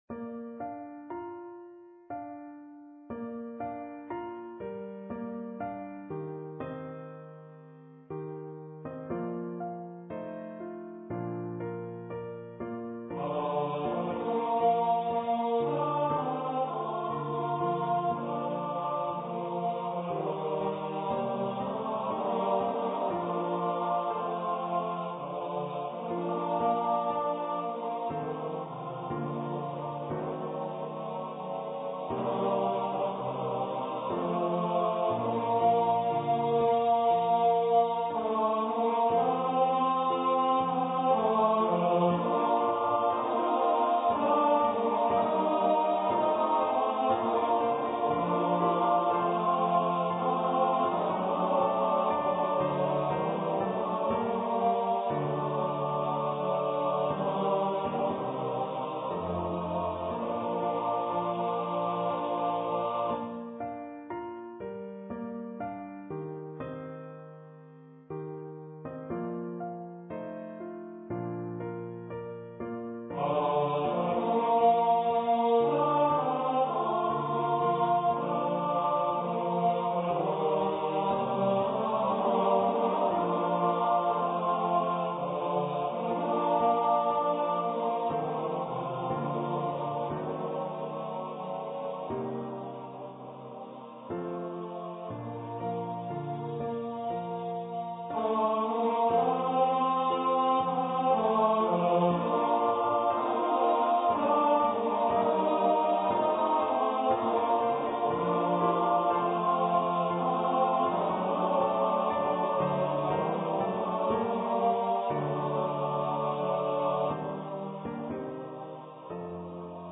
for male voice choir and piano
An eighteenth century Scottish melody
male voice choir (TTBB) and piano
Choir - Male voices